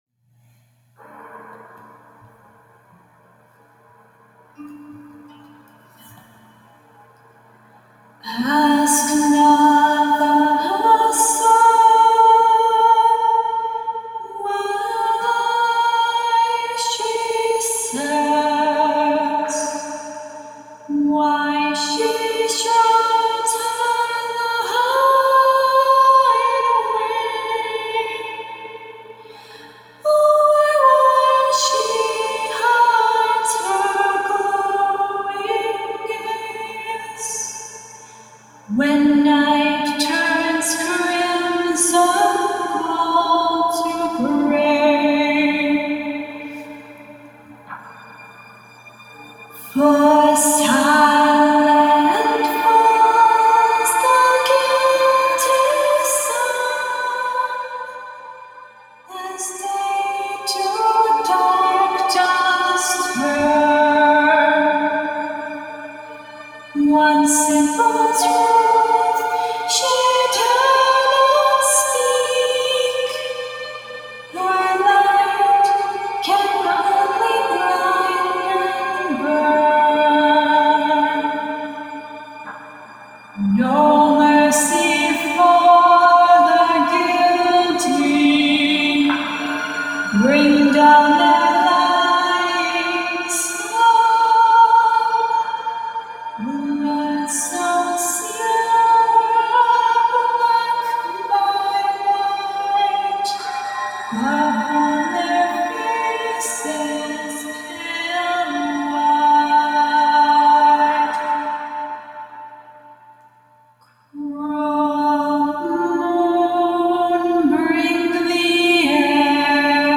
Me Singing